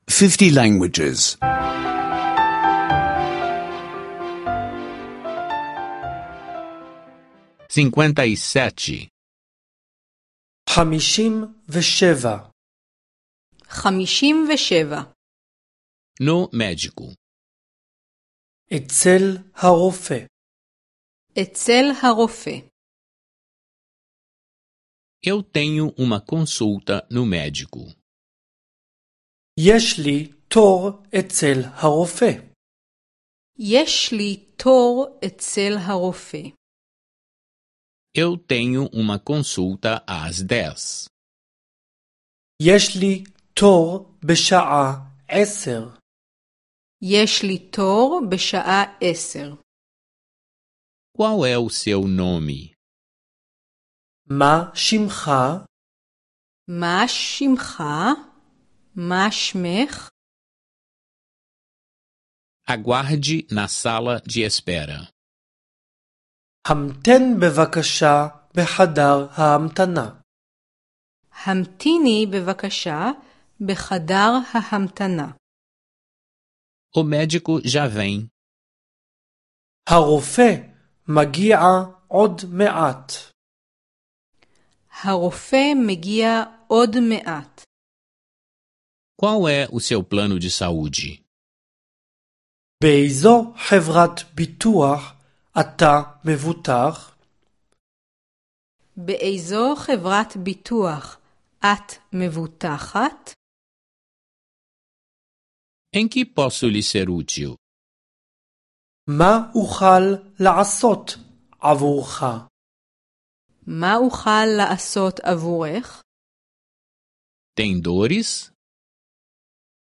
Aulas de hebraico em áudio — download grátis